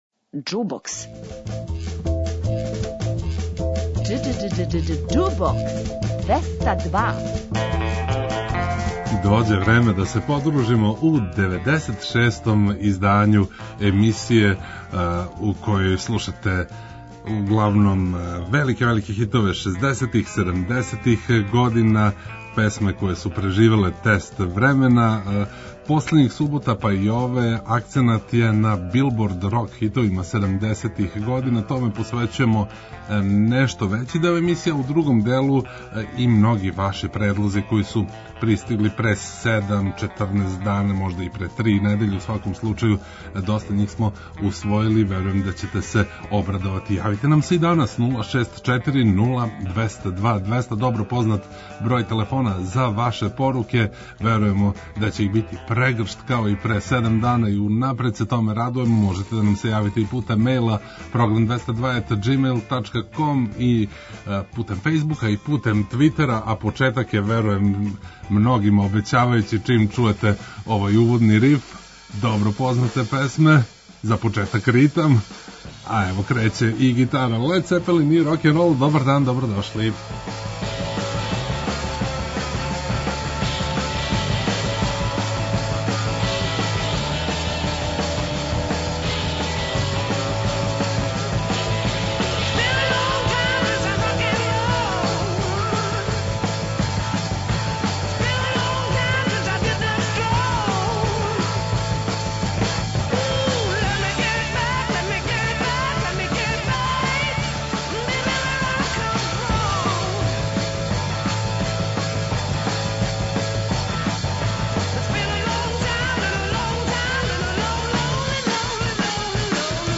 Хитови са Билбордове рок листе седамдесетих и ваши предлози